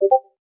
サイレン 無料ダウンロードとオンライン視聴はvoicebot.suで